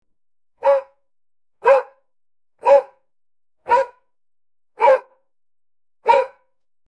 Pejsek
Pejsek hlídá babiččinu zahrádku a zastraší každého svým: „Haf haf!“ a je nejlepší kamarád na hraní i mazlení.
pes.mp3